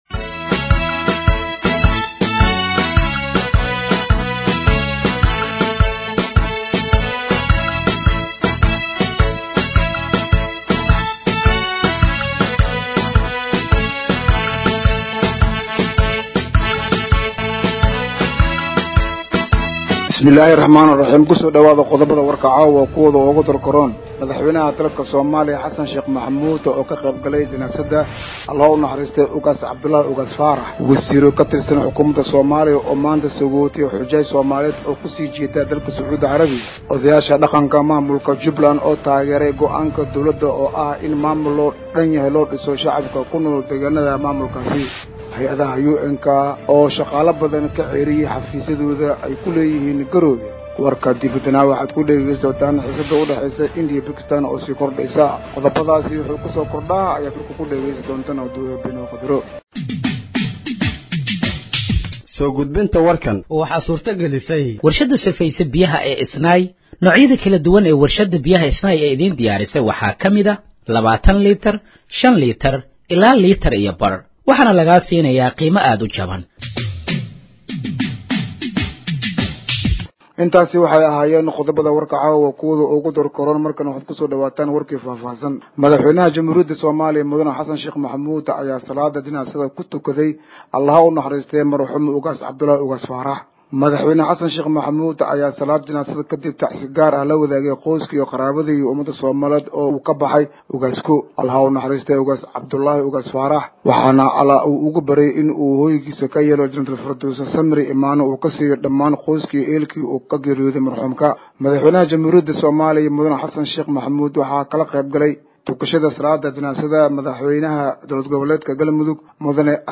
Dhageeyso Warka Habeenimo ee Radiojowhar 09/05/2025